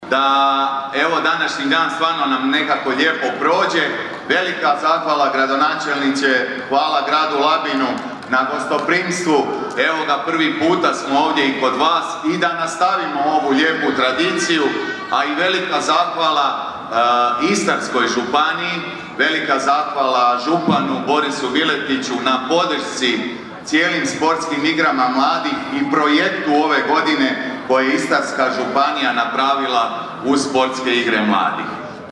Pozdravi i svečano paljenje plamena